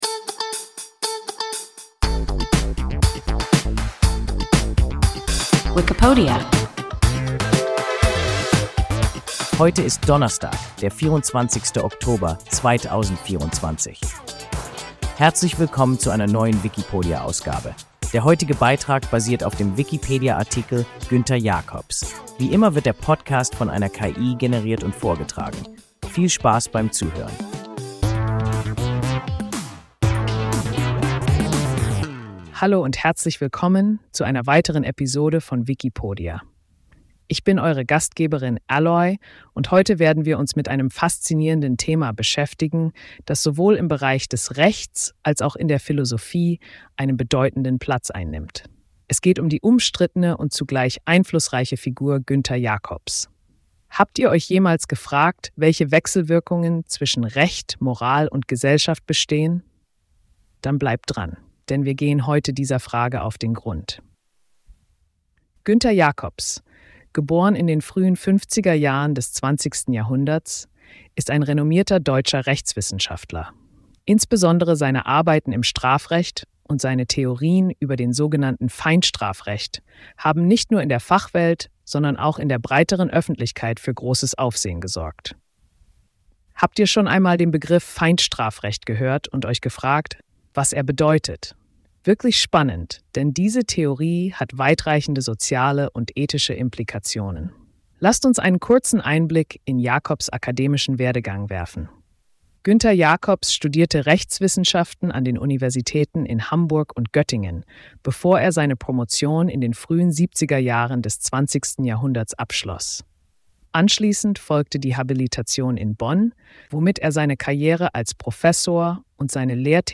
Günther Jakobs – WIKIPODIA – ein KI Podcast